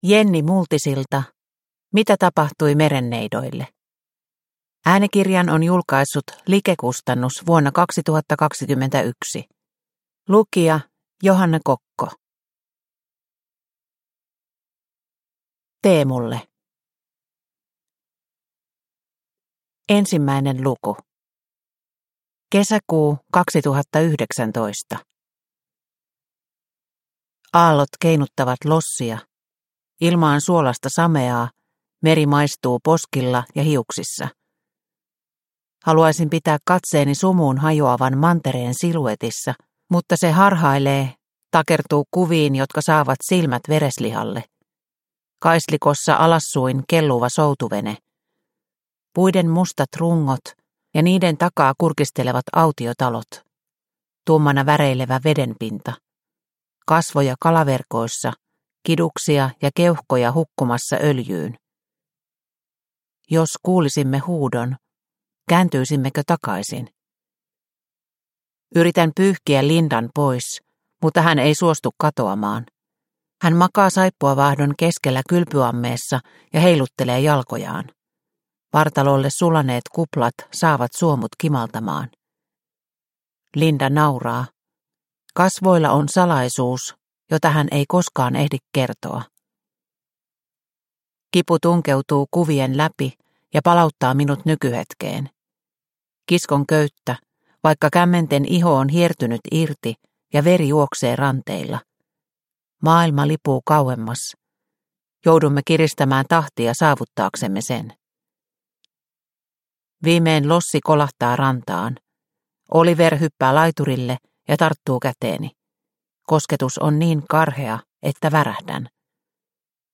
Mitä tapahtui merenneidoille – Ljudbok – Laddas ner